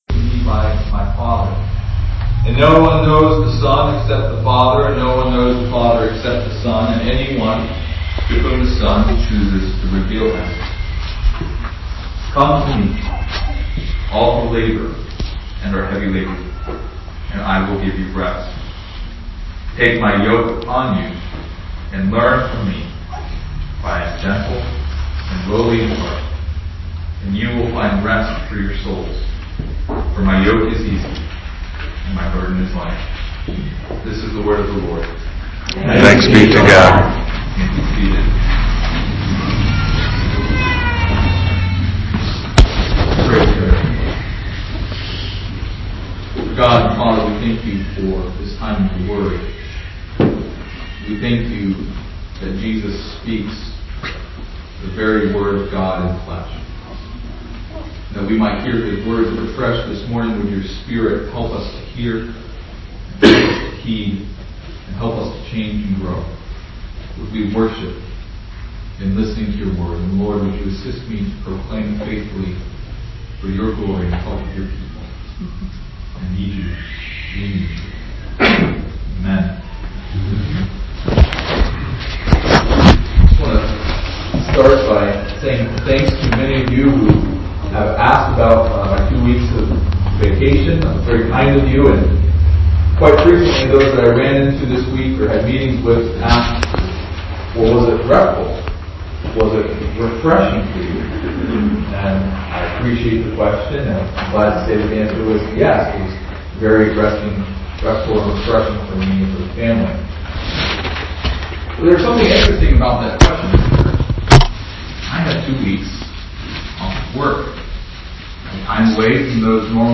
(Forgive the sound quality) Matthew 11:25-30